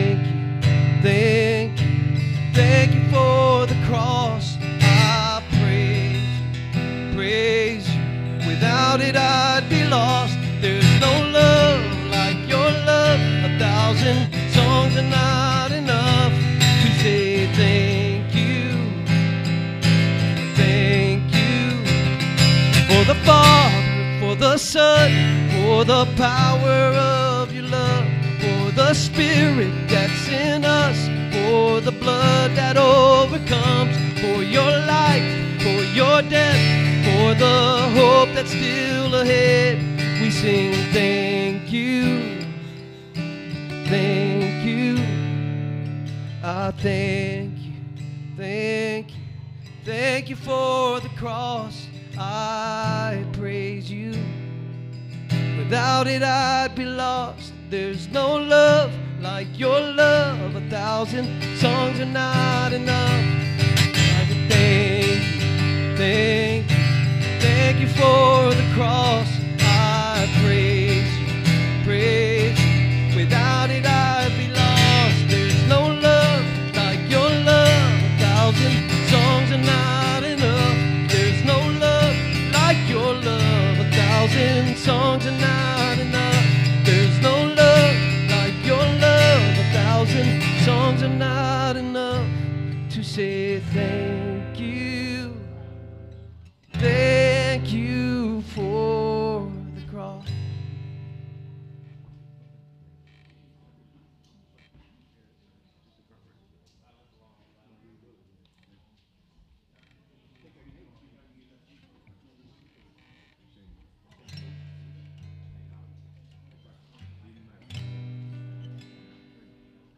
HOLY WEEK 2026 Current Sermon